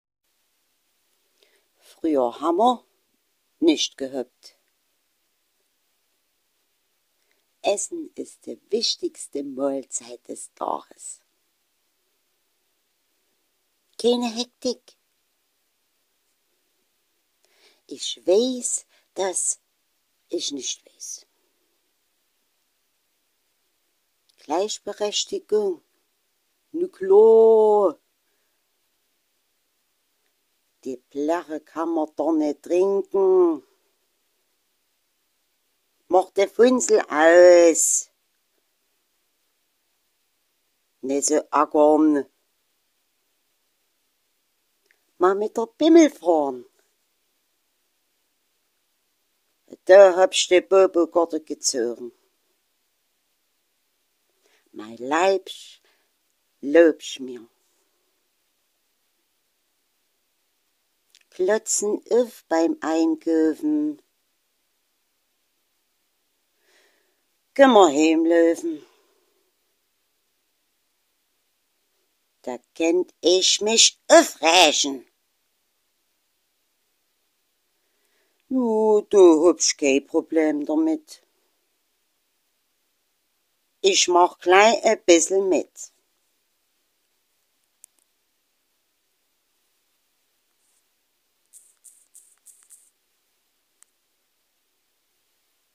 Die SDGs auf Sächsisch